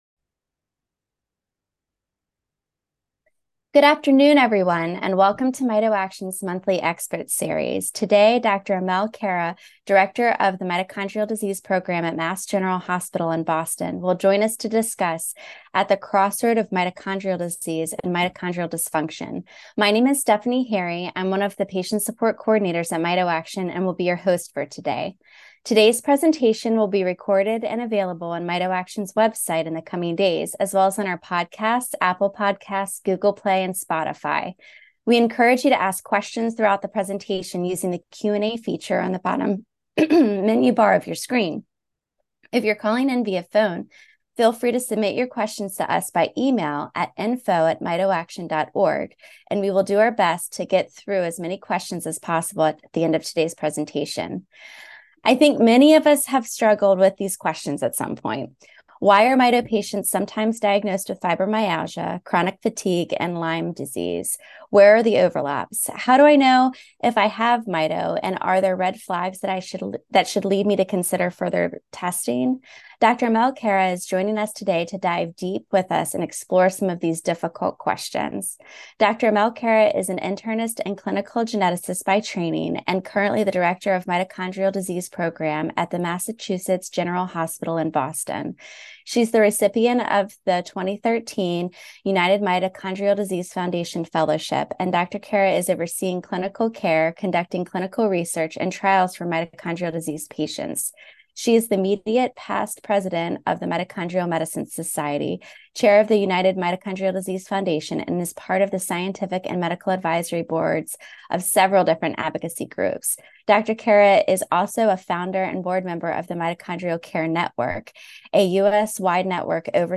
a discussion about misdiagnosed mitochondrial disease.